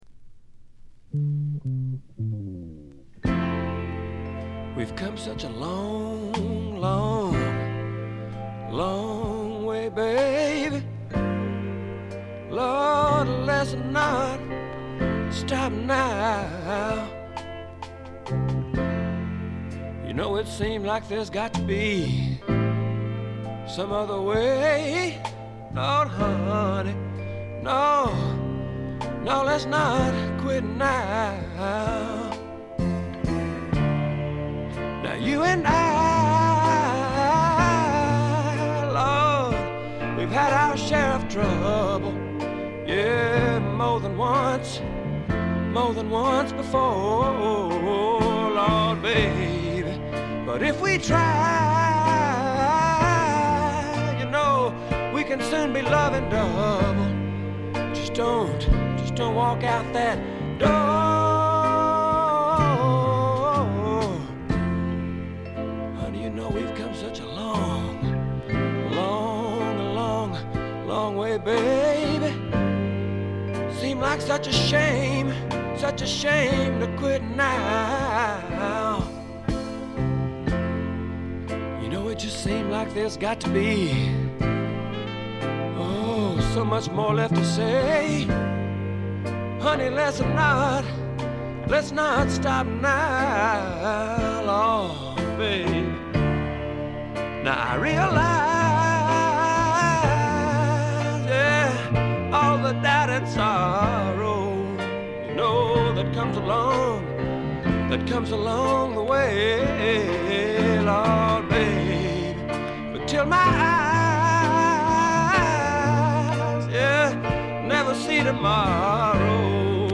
部分試聴ですが、ごくわずかなノイズ感のみ。
あまりナッシュビルぽくないというかカントリーぽさがないのが特徴でしょうか。
試聴曲は現品からの取り込み音源です。
Guitar, Vocals, Piano, Vibes